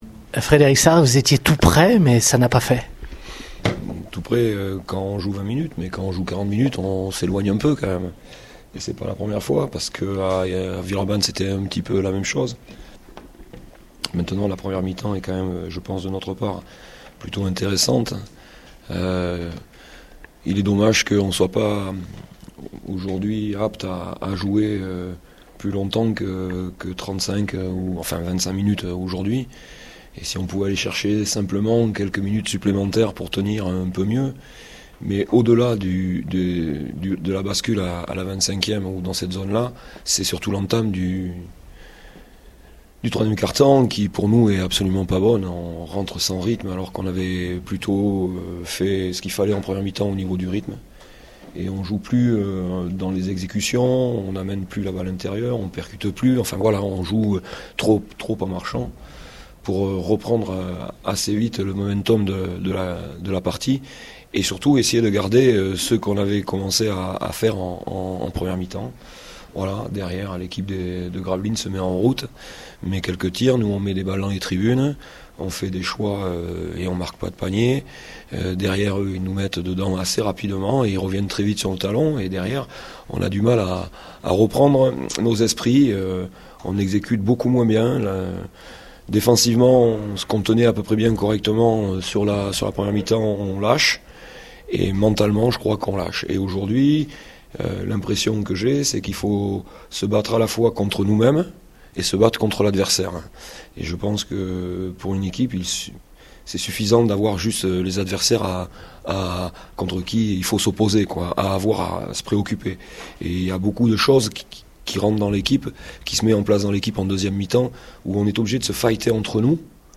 réactions d’après-match au micro Radio Scoop